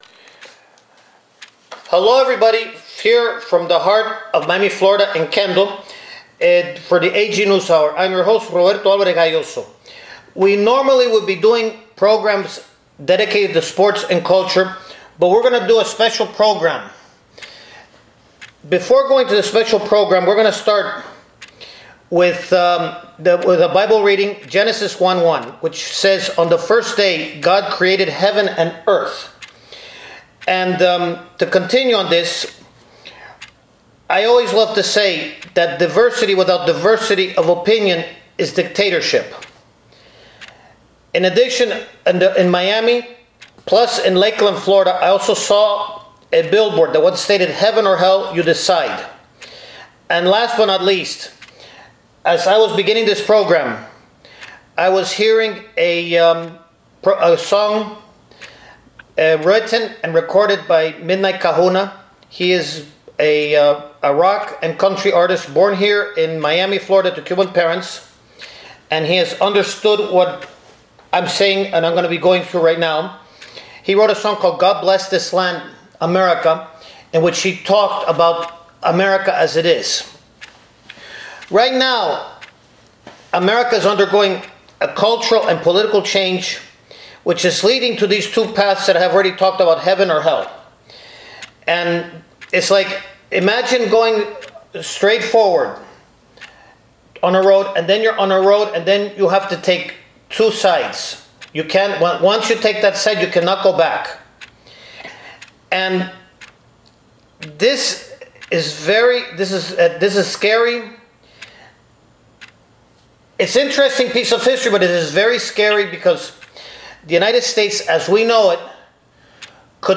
COMMUNISM : 21 ST CENTURY THREAT TO AMERICA (AN INTERVIEW